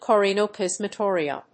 • : -ɪəɹiəm